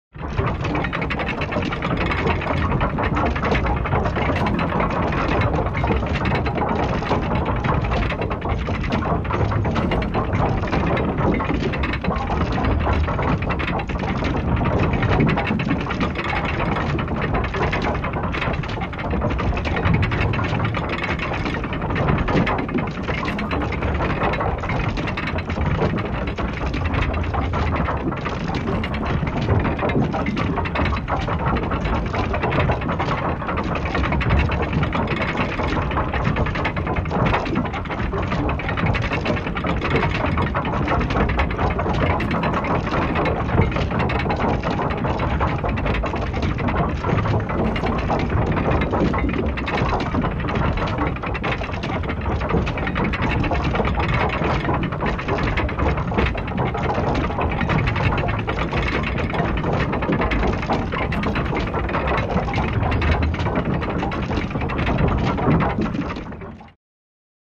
Звук шестеренок
Звук шестеренок подъемного моста:
zvuk-shesterenok-podemnogo-mosta.mp3